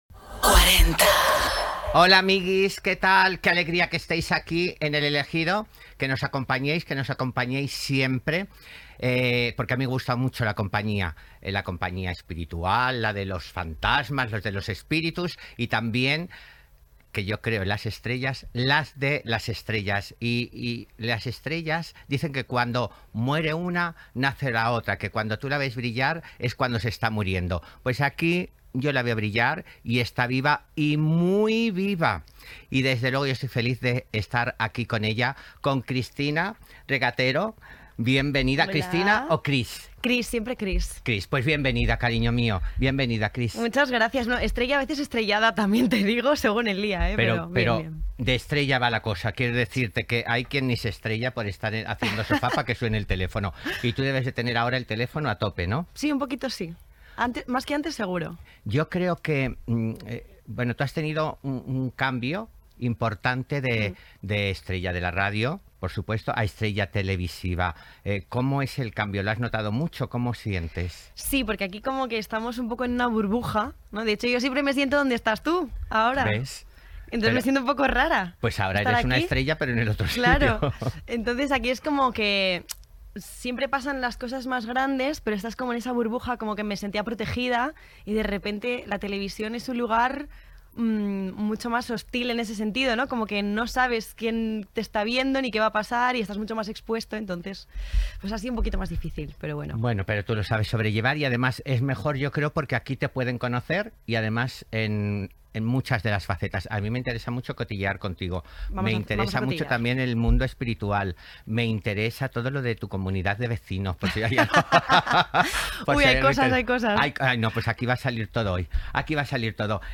Indicatiu de la ràdio, presentació, entrevista
Gènere radiofònic Entreteniment